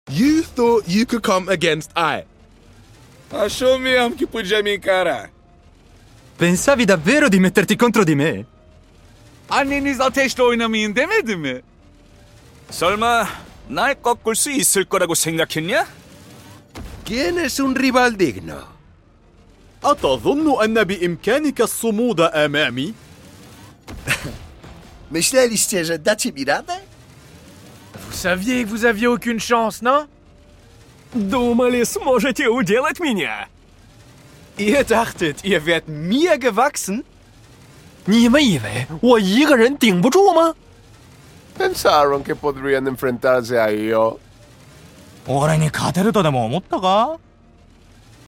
Phoenix Ace Voice Lines in sound effects free download
Phoenix Ace Voice Lines in Every Language (Part 2) | Valorant